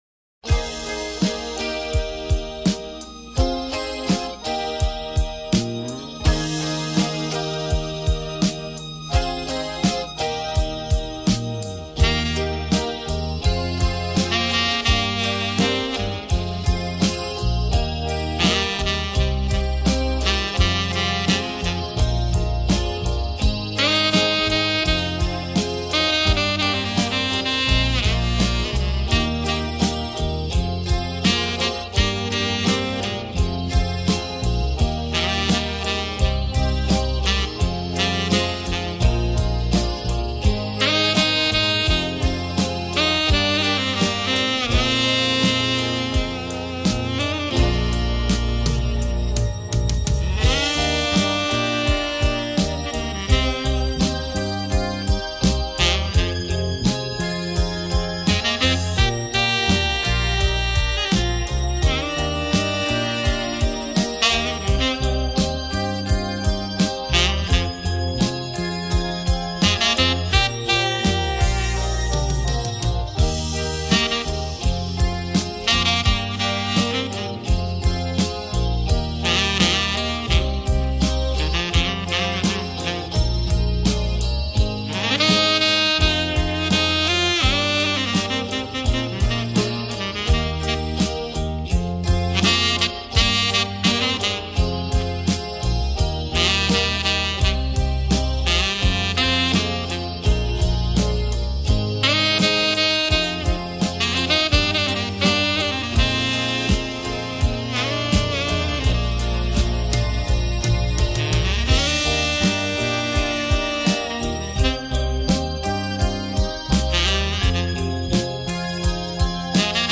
【浪漫萨克斯】
Instrumental | MP3 | VBR 320 kbit
Joint Stereo/44100 Hz
及其它类型音乐中，表现出杰出的抒情，浪漫风格普遍受到人们的喜爱。